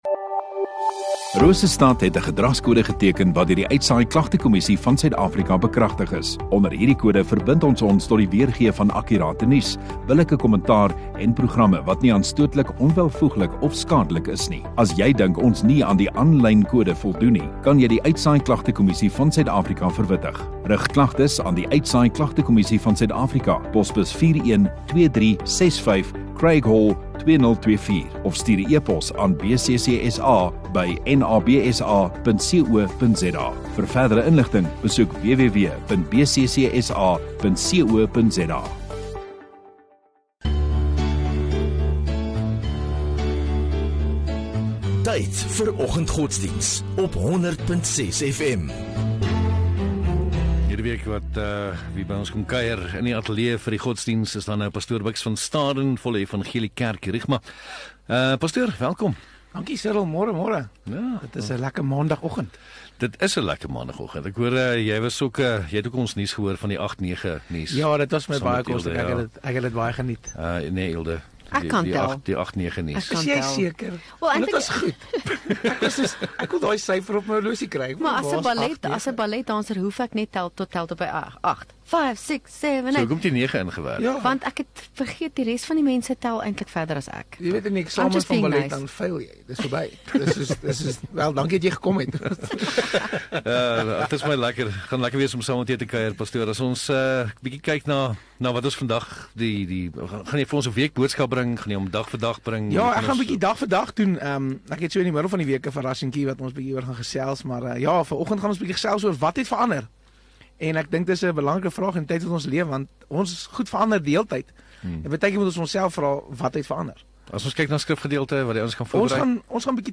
18 Mar Maandag Oggenddiens